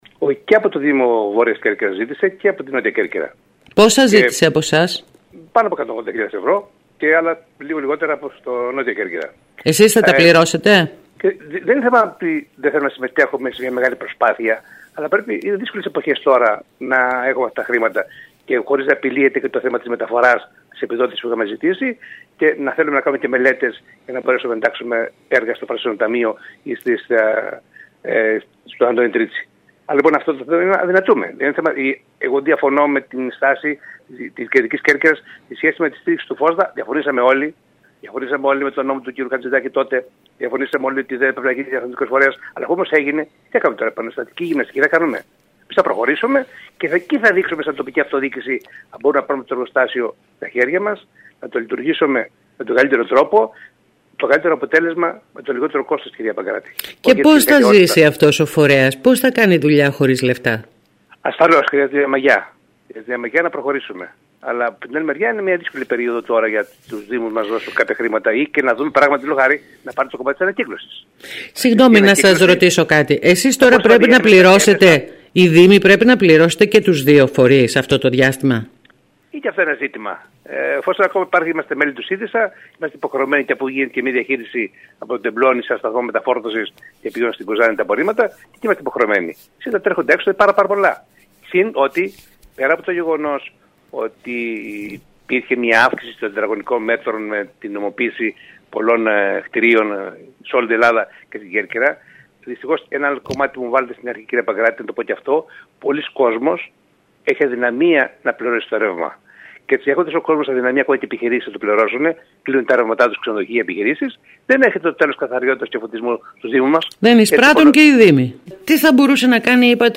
Ρεπορτάζ